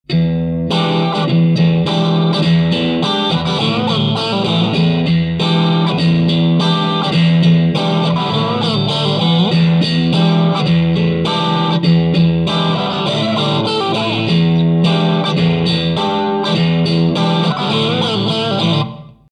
JH FUZZ OFF(302kbMP3)
Guitar Fender STRTOCASTER
Amplifier VOX AD30VT UK70'GAIN10
VOLUME10,TREBLE10,MIDDDLE10,BASS6